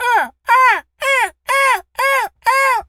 seagul_squawk_seq_07.wav